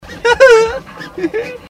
Laugh 20